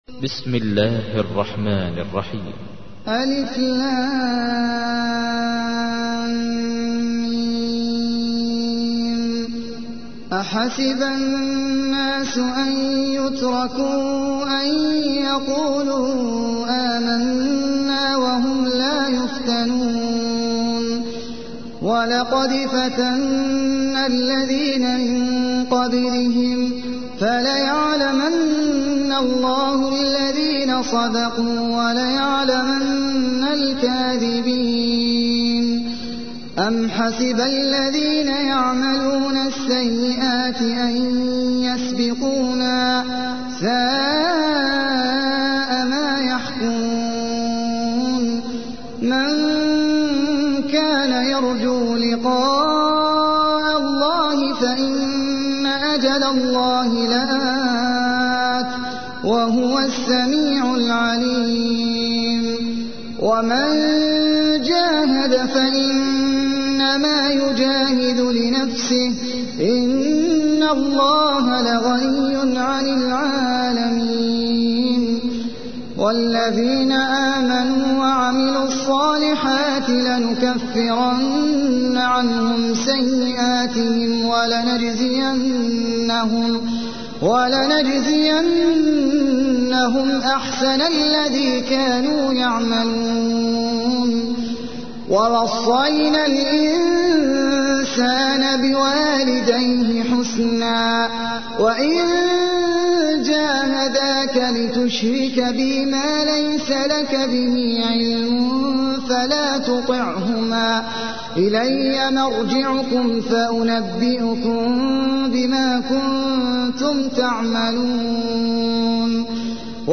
تحميل : 29. سورة العنكبوت / القارئ احمد العجمي / القرآن الكريم / موقع يا حسين